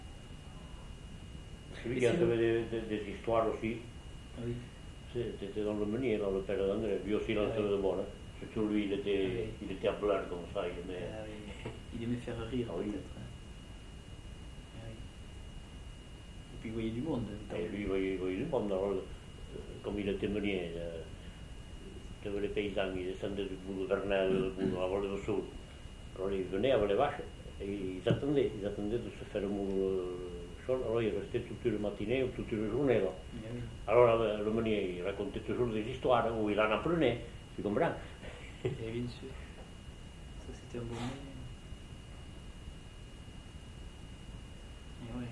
Aire culturelle : Couserans
Lieu : Pouech de Luzenac (lieu-dit)
Genre : témoignage thématique